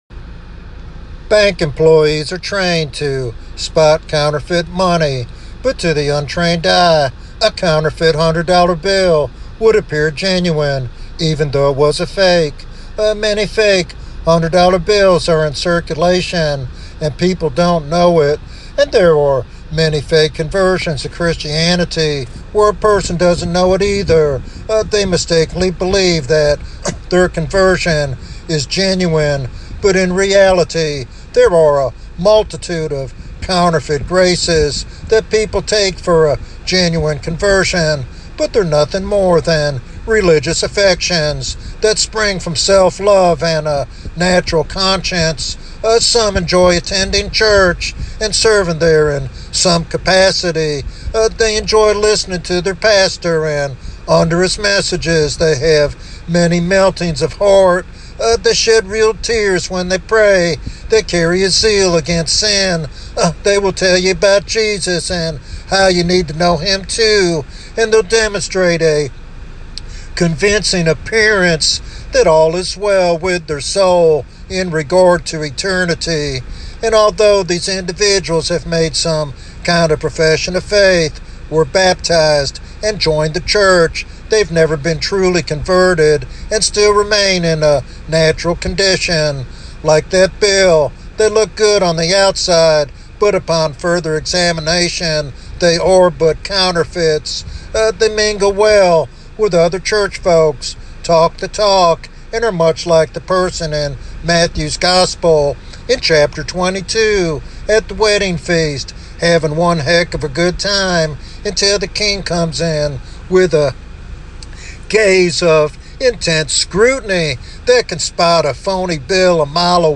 This sermon calls for genuine repentance and a heartfelt commitment to Christ's righteousness.